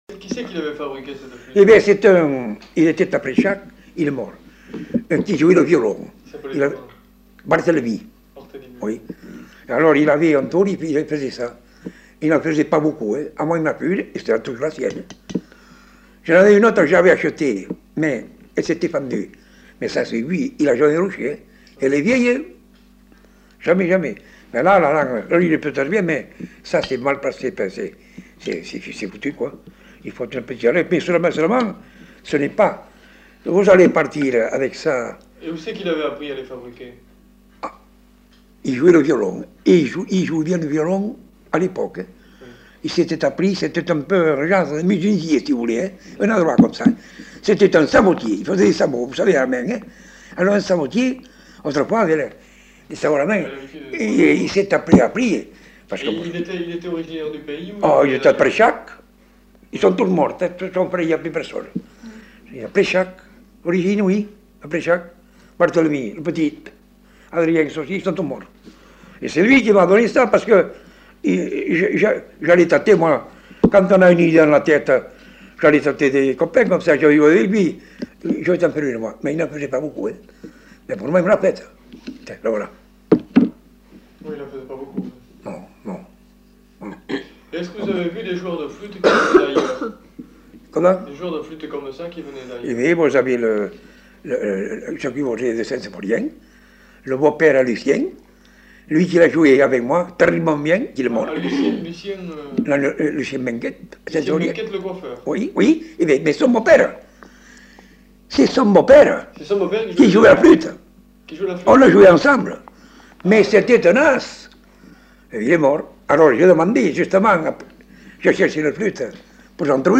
Genre : témoignage thématique
Instrument de musique : flûte à trois trous ; violon